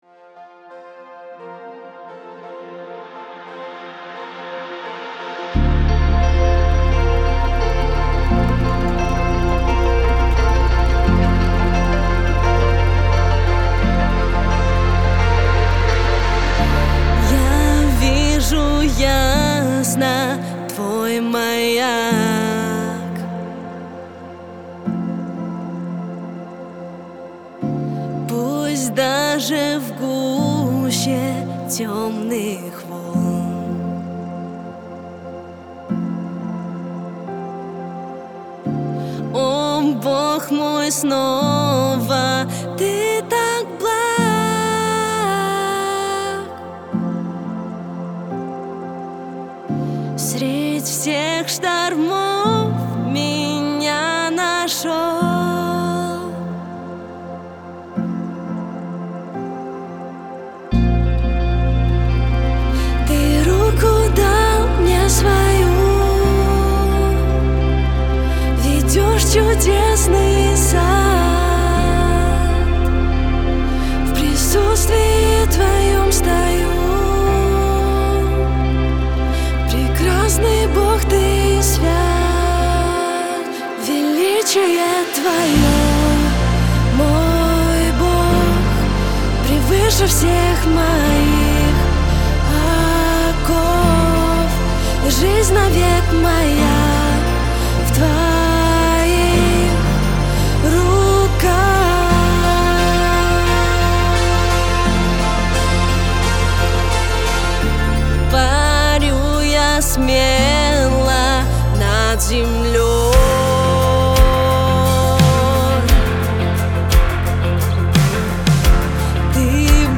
391 просмотр 345 прослушиваний 42 скачивания BPM: 87